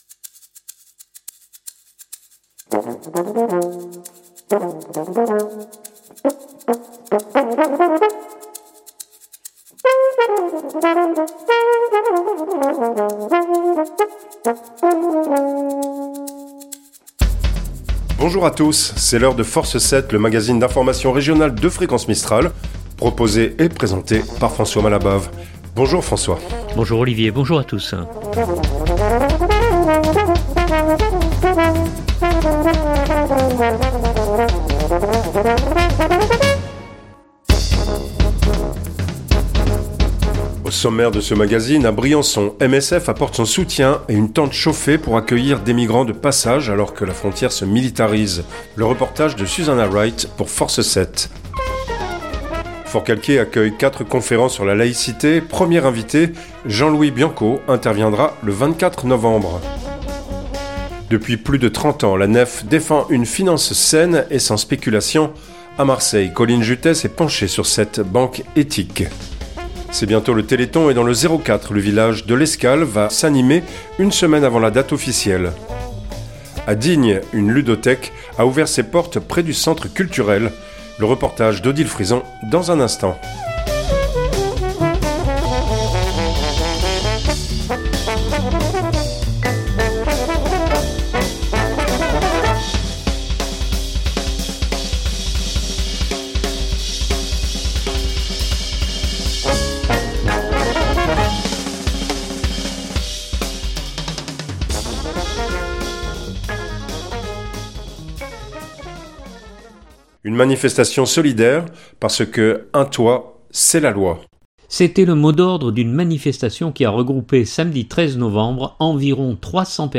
un magazine d’information régional